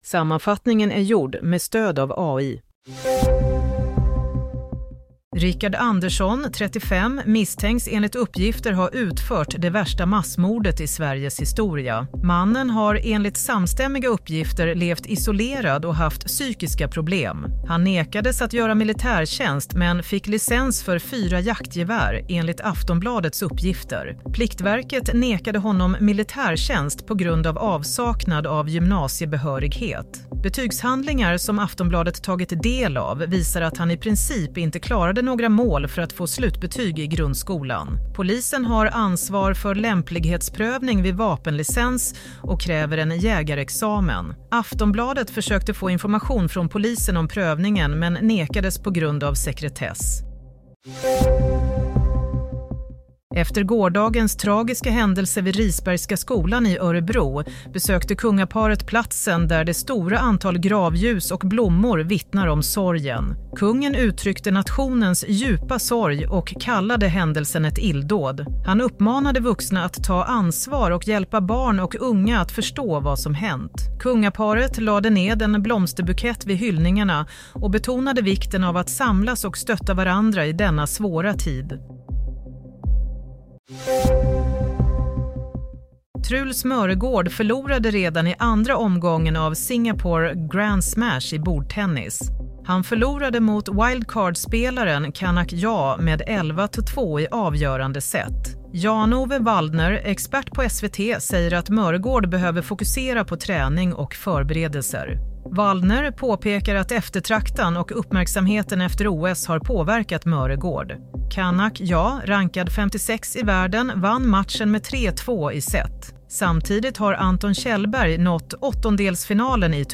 Nyhetssammanfattning - 5 februari 16.45
Sammanfattningen av följande nyheter är gjord med stöd av AI.